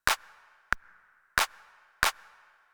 Here you will find rhythms with various types of notes in the bar.
One half note and two quarter notes.